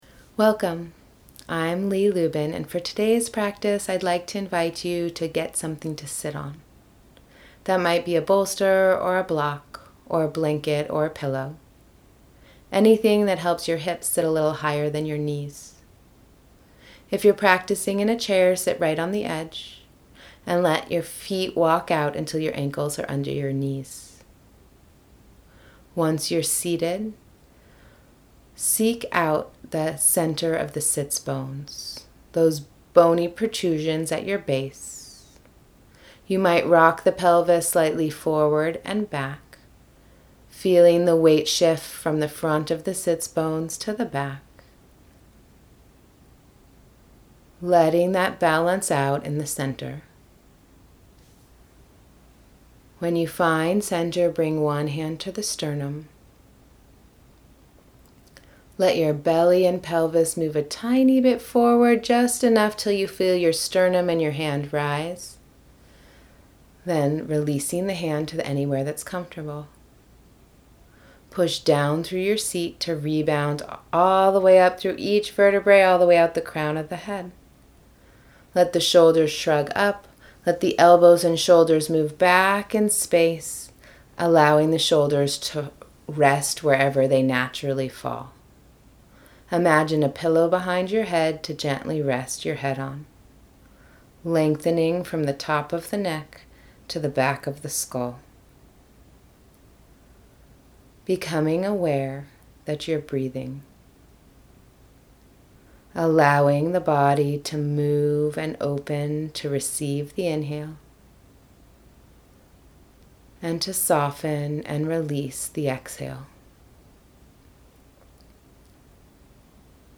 Guided pranayama (breath practice) leads into pratyahara (internal awareness) and a content stillness.